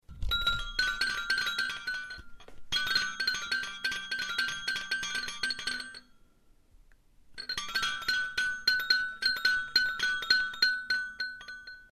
На этой странице вы найдете звуки неваляшки — знакомые с детства мелодичные переливы и покачивания.
звон трель неваляшки звук